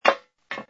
sfx_fstop_steel_female03.wav